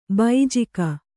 ♪ baijika